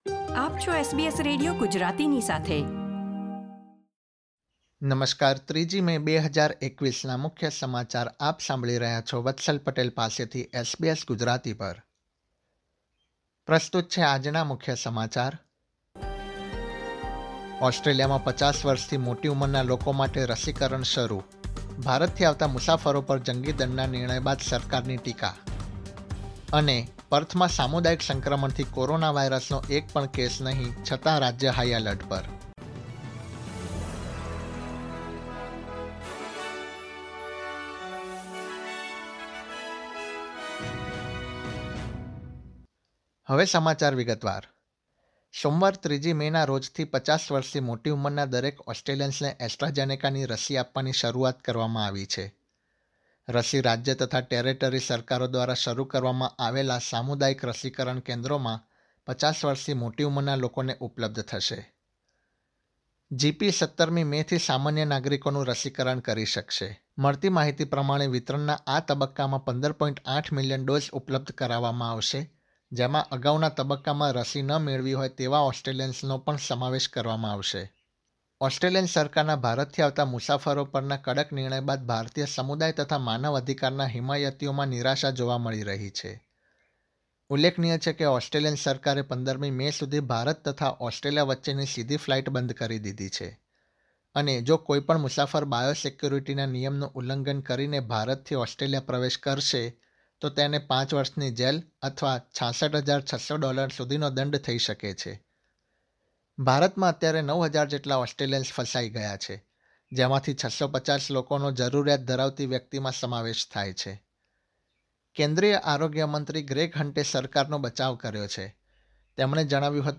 gujarati_0305_newsbulletin.mp3